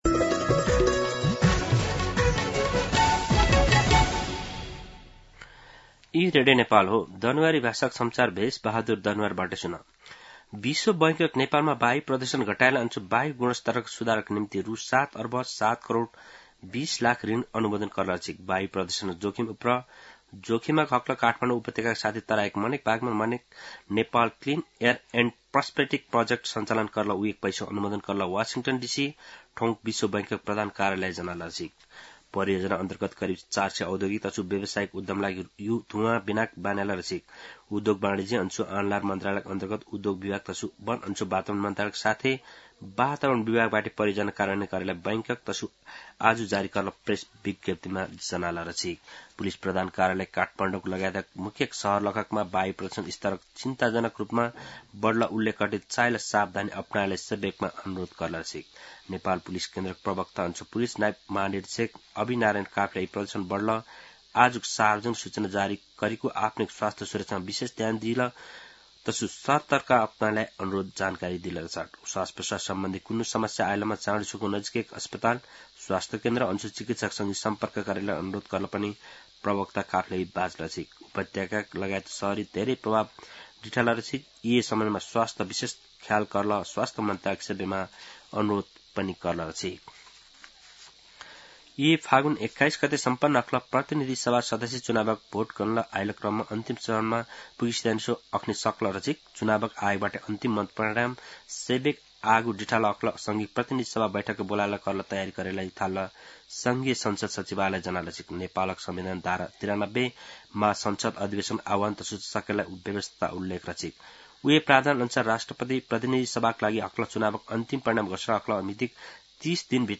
दनुवार भाषामा समाचार : २७ फागुन , २०८२